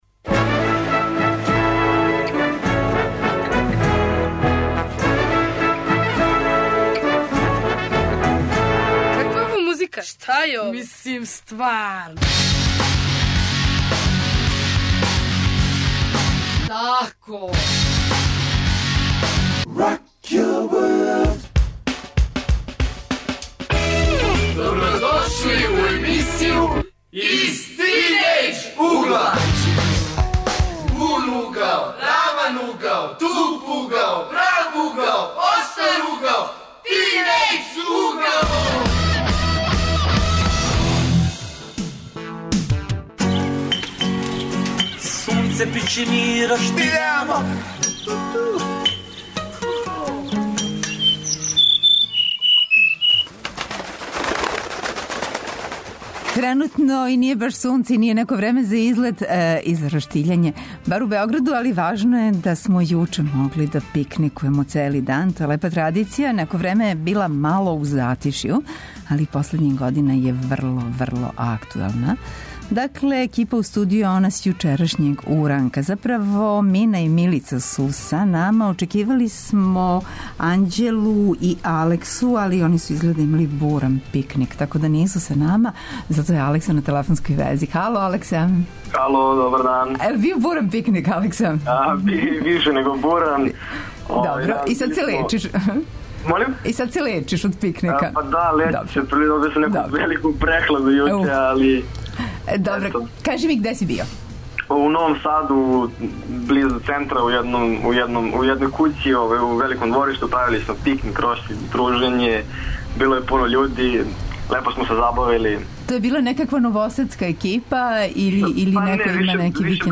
Данас причамо о излетима јер смо још увек у духу Првог маја. Са младим људима одговарамо на нека од питања у вези са излетима.
Укључују нам се саговорници широм Србије, то су млади који ће нам рећи где и како се код њих организују излети.